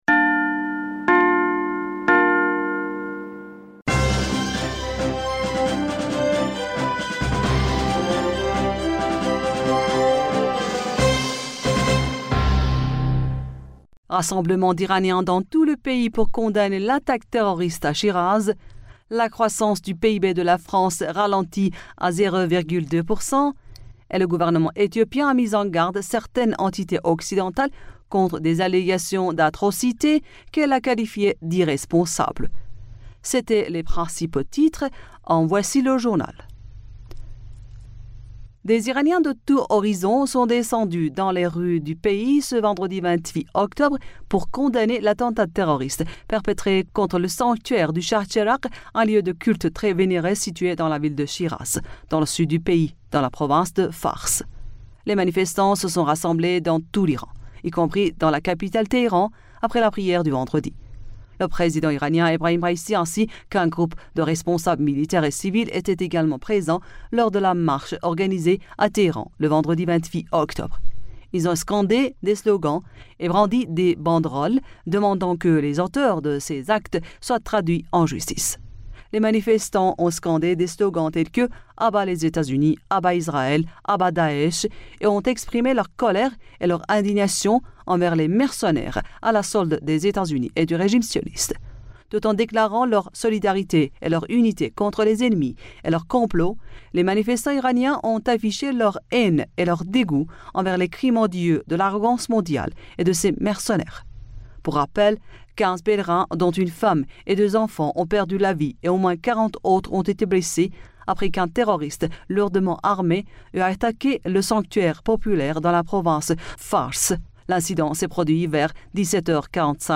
Bulletin d'information Du 29 Octobre